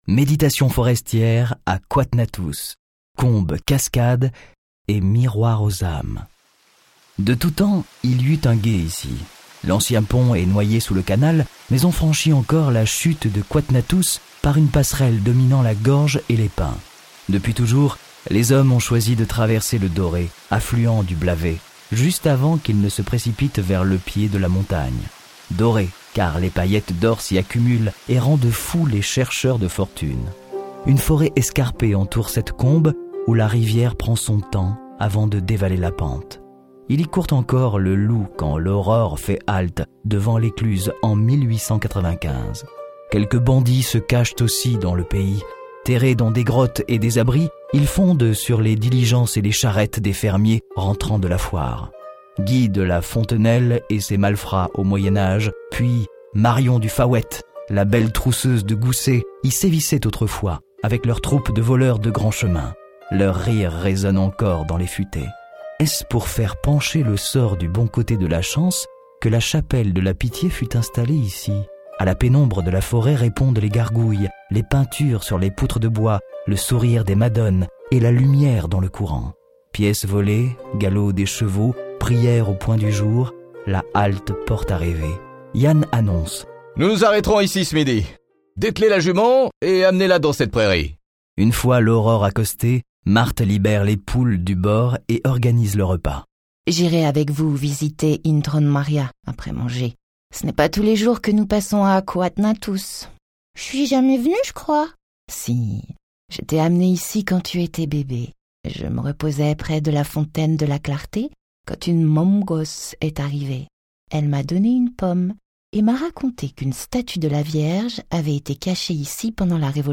Bienvenue dans un récit au fil des âges, au fil de l’eau. Découverte, histoire, légendes et émotions se mêlent sur fond de bruits d’eau, de chants d’oiseaux, et de musique bretonne. S’y ajoutent les témoignages des acteurs d’aujourd’hui : habitants riverains, éclusiers, restaurateurs, animateurs nature, conteur. Des paroles émouvantes, des ambiances sonores inédites.